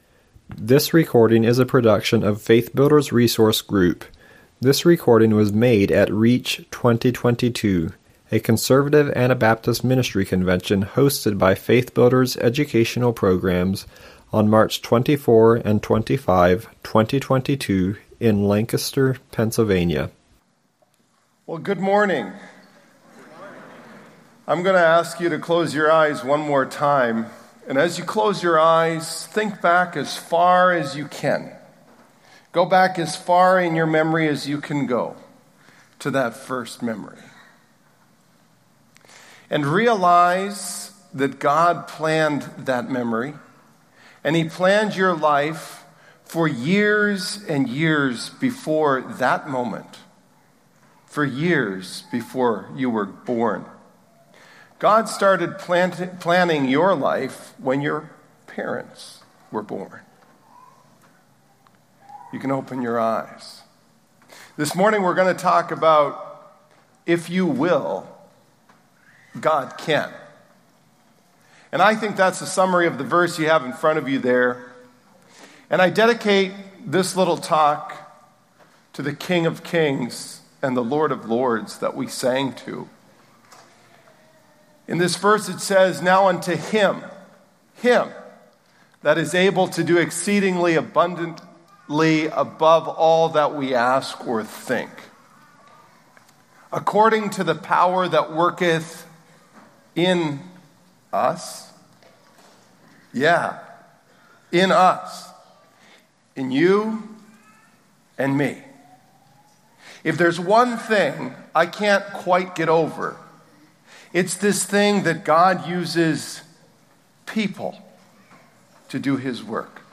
REACH 2022